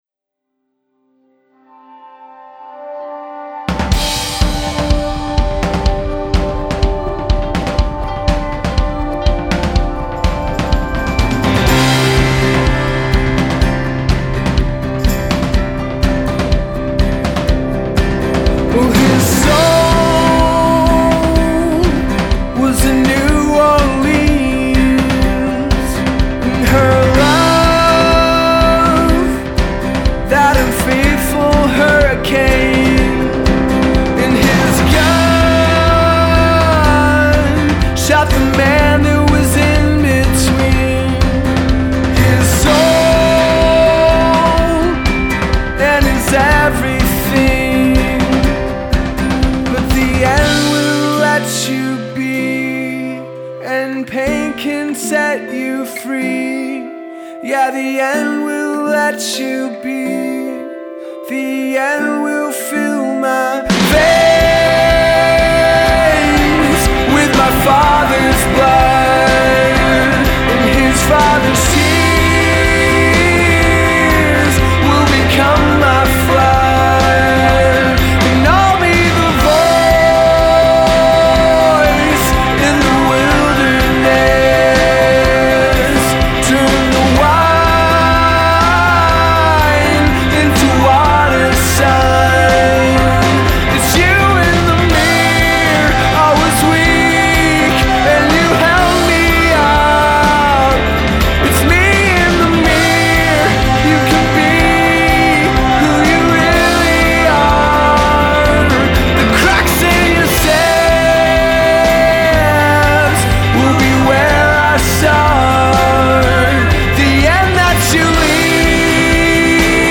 This track is poppy….